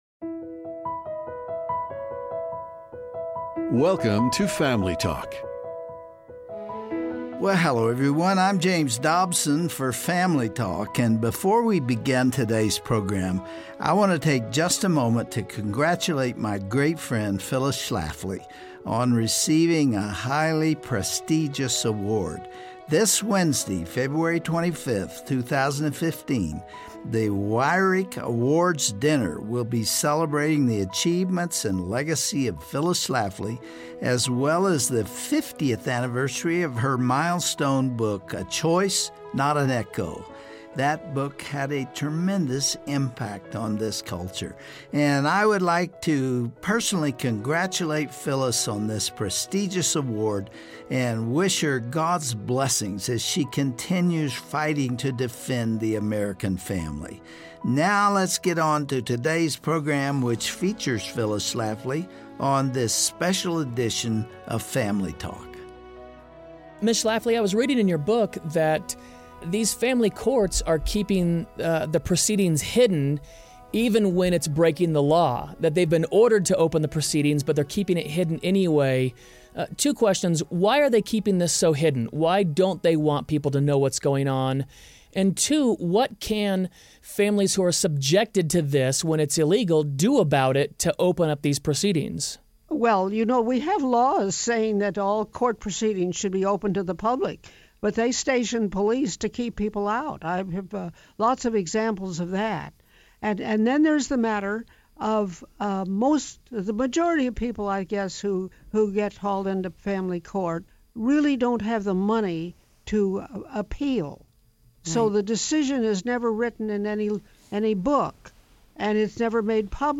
Dr. Dobson concludes his interview with Phyllis Schlafly about the state of the American Family today...
Host Dr. James Dobson